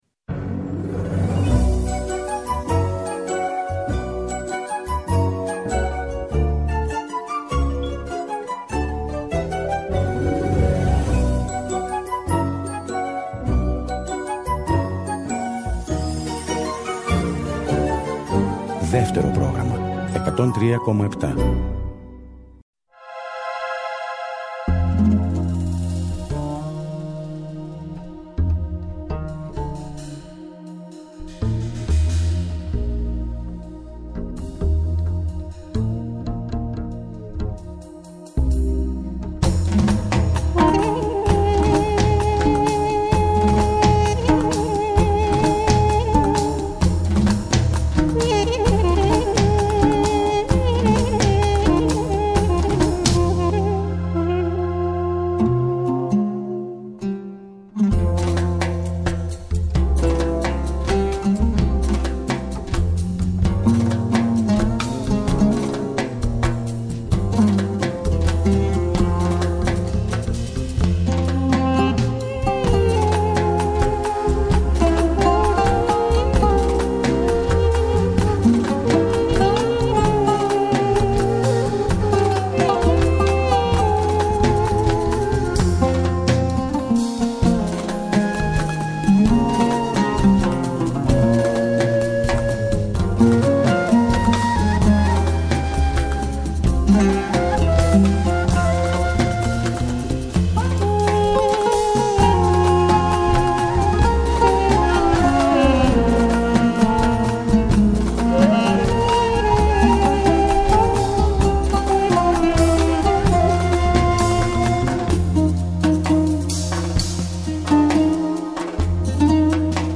Αρμένικες και Βαλκάνιες μελωδίες σε Ελληνικές ερμηνείες.